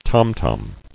[tom-tom]